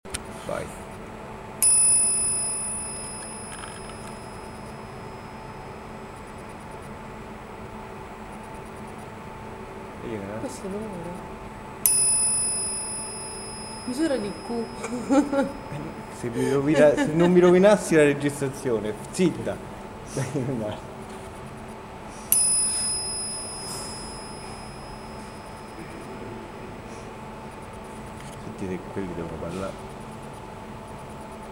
RAP Bar Sound